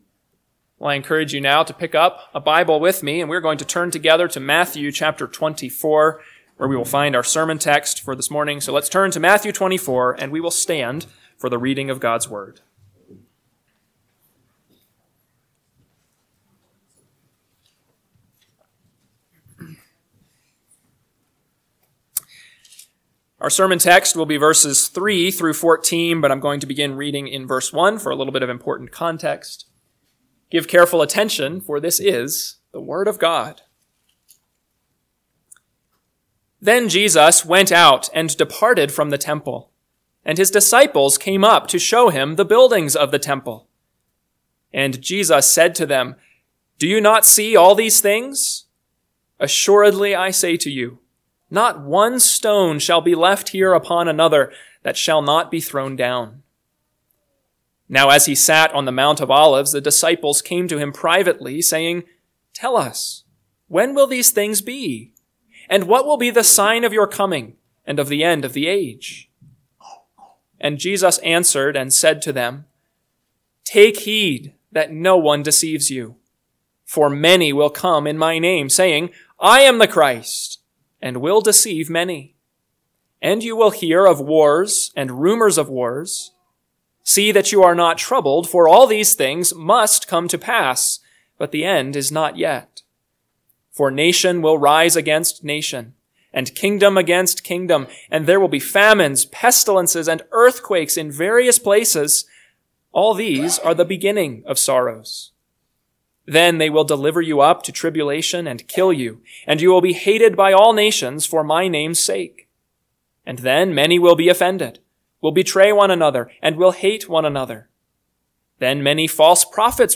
AM Sermon – 12/8/2024 – Matthew 24:3-14 – Northwoods Sermons